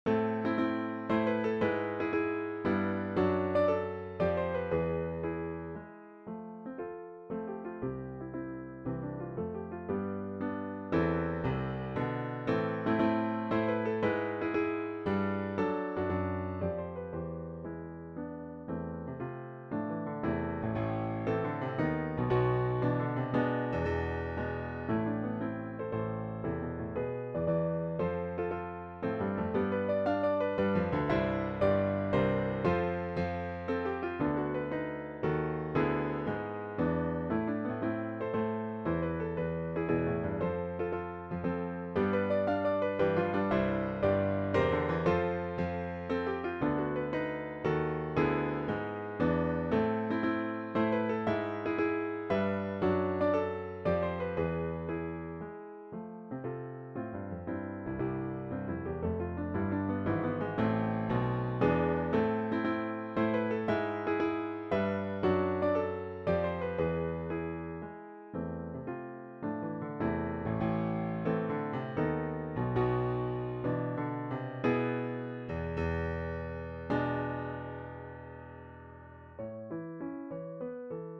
Minuet por piano in A minor - Piano Music, Solo Keyboard
I recomposed it because the bass was very simple (just octaves in half notes), and it didnt have the form of a minuet. at least now it haves the form I include an harmonic analysi...